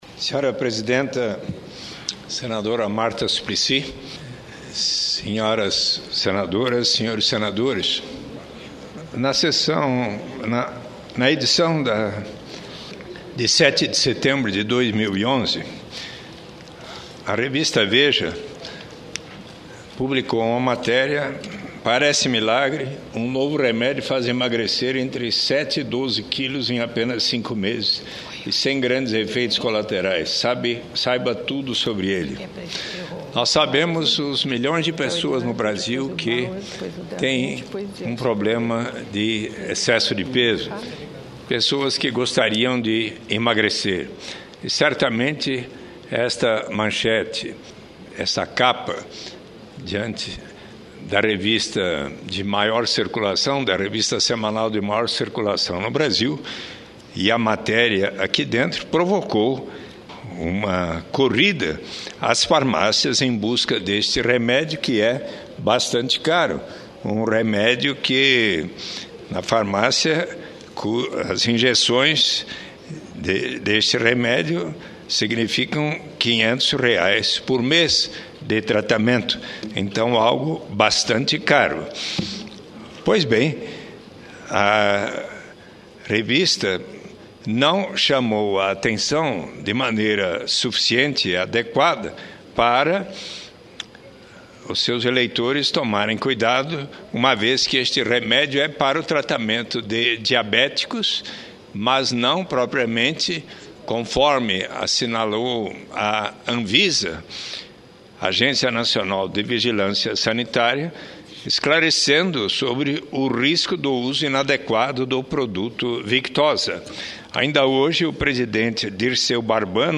No entanto, a Anvisa esclareceu que o Victoza só deve ser utilizado para fins terapêuticos em pacientes diabéticos. Suplicy leu a nota da Anvisa na íntegra.